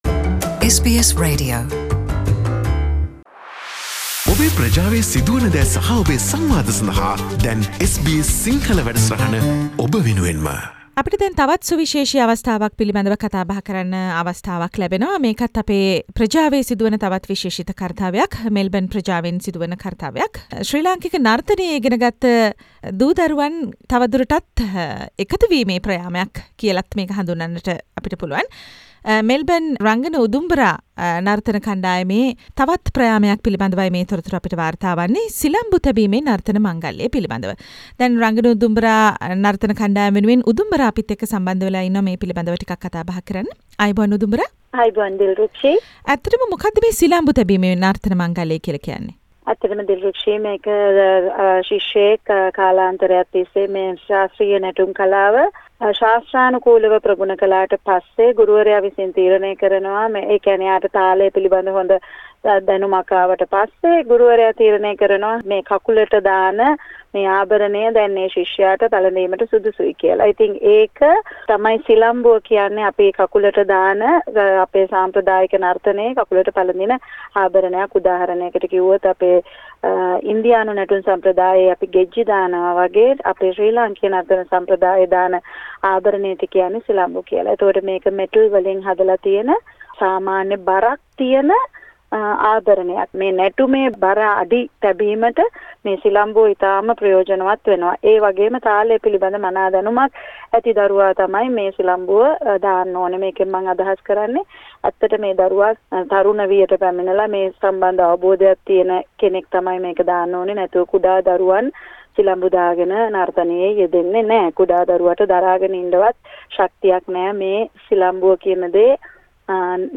SBS සිංහල ගුවන් විදුලිය සමඟ පැවසූ අදහස්